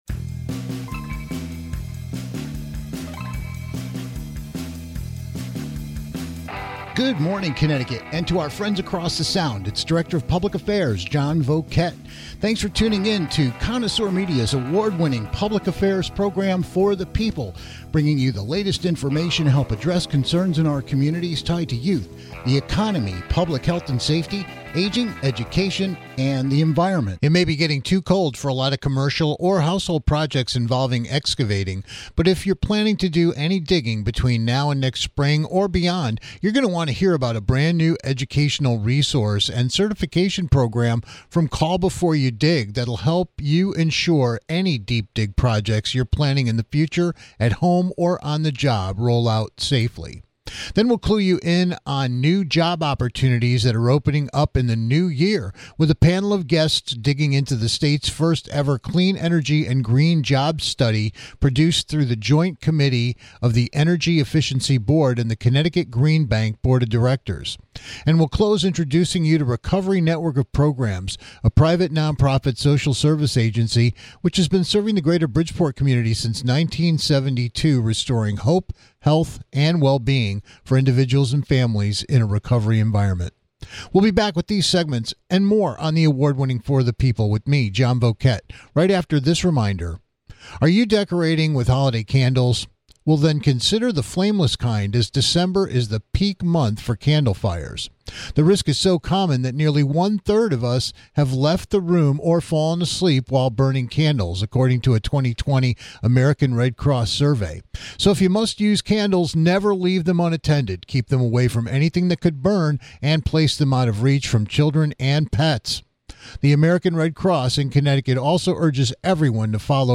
Then we'll clue you in on new job opportunities that are opening up in the new year with a panel of guests digging into the state's first ever clean energy and green jobs study produced through the Joint Committee of the Energy Efficiency Board (EEB) and the Connecticut Green Bank Board of Directors.